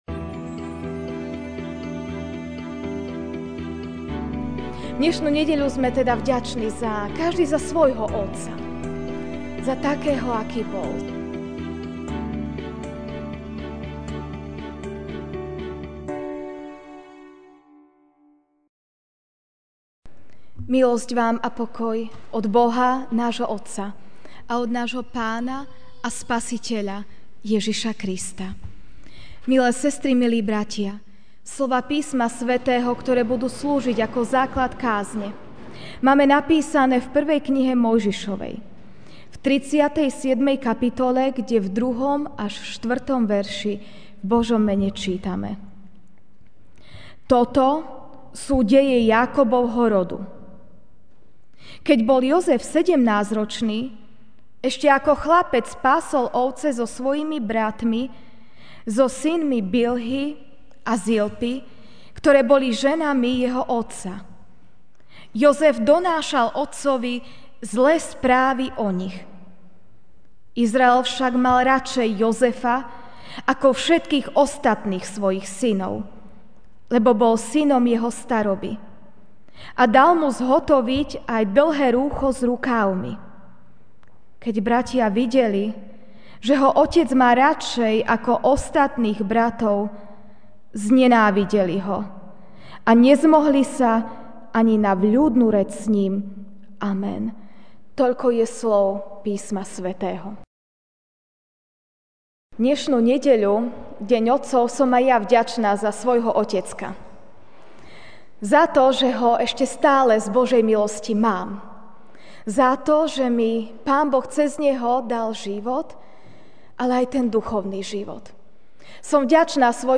Ranná kázeň: Deň otcov (1. M. 37, 2-4) Toto sú deje Jákobovho rodu: Keď bol Jozef sedemnásťročný, ešte ako chlapec pásol ovce so svojimi bratmi, so synmi Bilhy a Zilpy, ktoré boli ženami jeho otca; Jozef donášal otcovi zlé správy o nich.